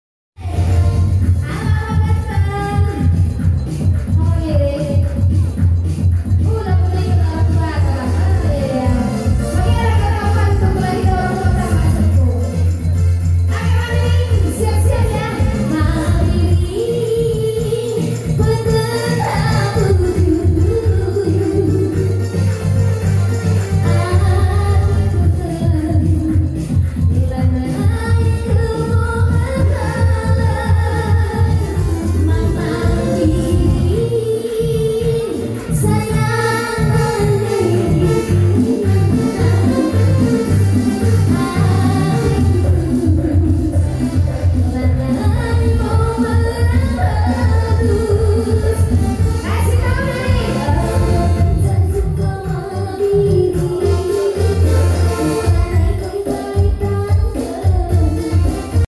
KERJA TAHUN KUTA SUKADAME